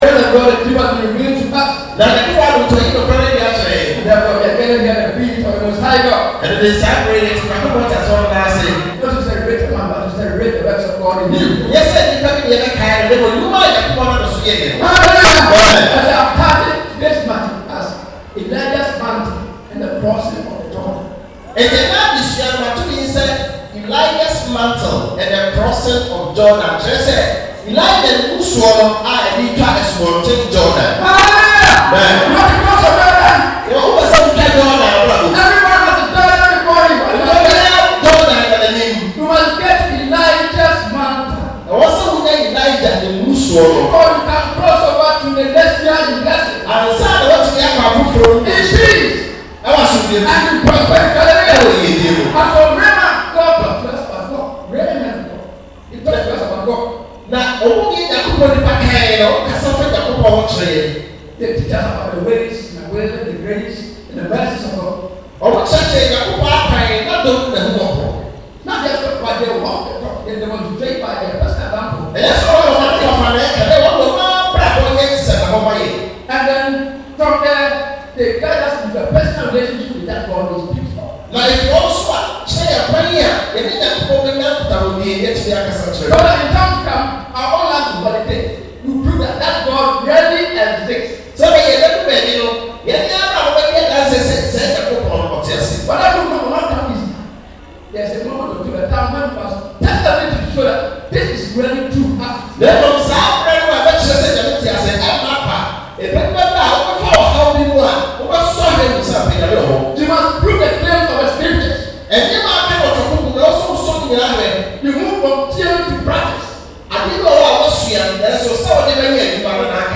2017 End-of-Year Camp Meeting Sermon